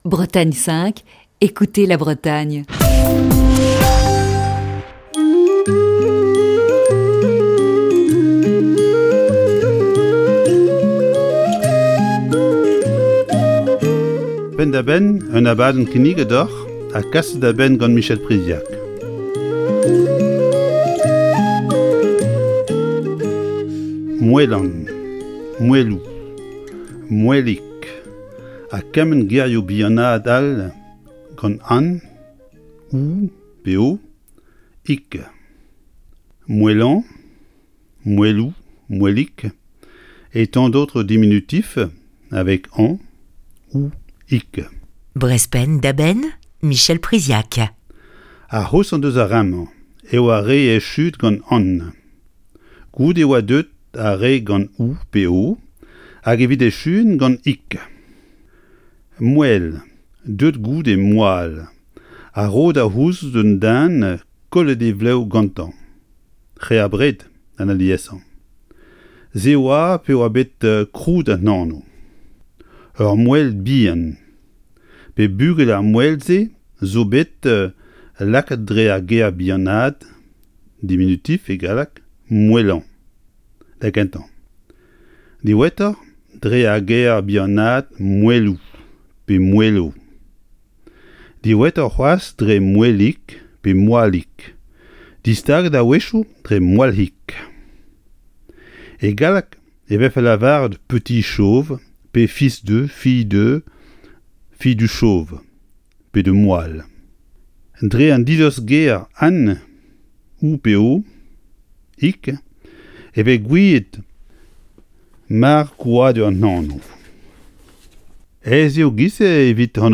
Chronique du 12 novembre 2020. Cour de breton, ce jeudi dans Breizh Penn da Benn.